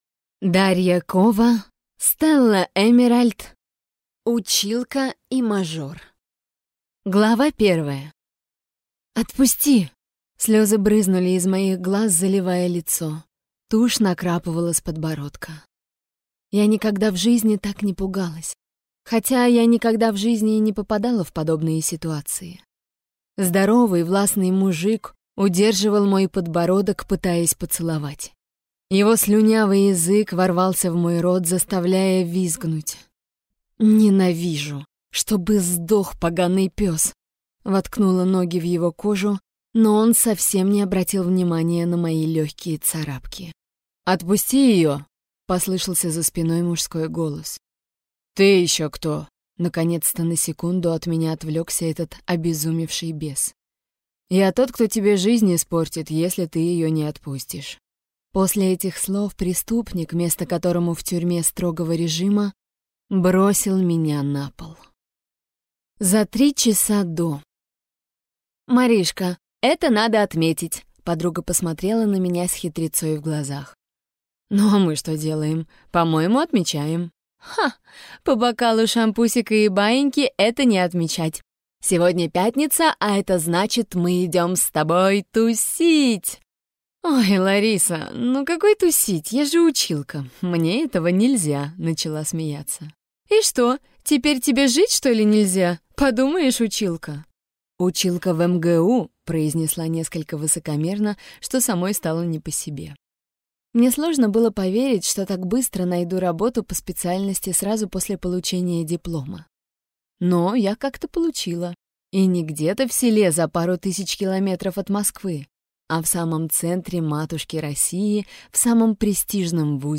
Аудиокнига Училка и мажор | Библиотека аудиокниг